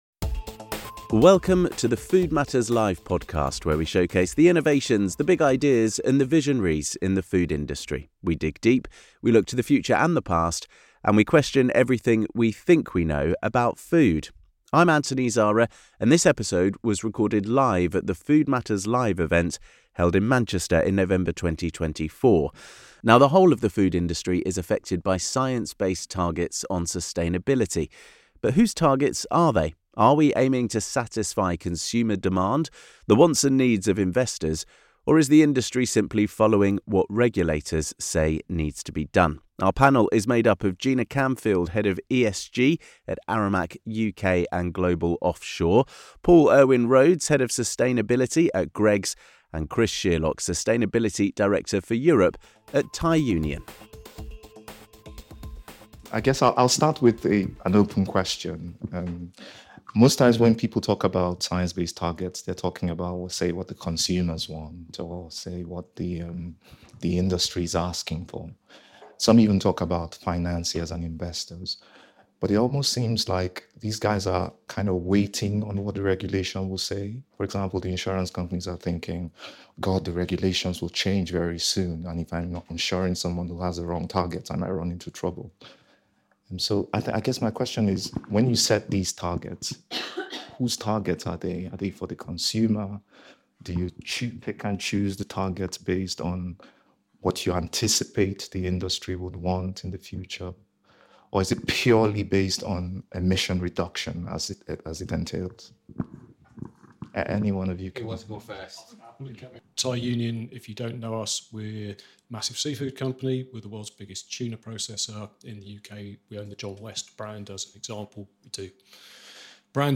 In this episode of the Food Matters Live podcast, recorded at our event in Manchester in November 2024, our expert panel offer their views on who is driving the push for sustainability. The discussion also delves into the challenges of data integrity, the significance of Scope 3 emissions, and the need for supplier engagement.